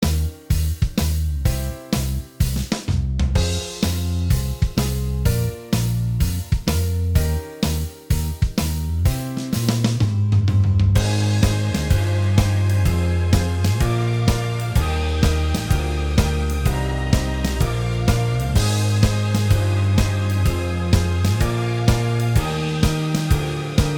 Minus All Guitars Comedy/Novelty 3:44 Buy £1.50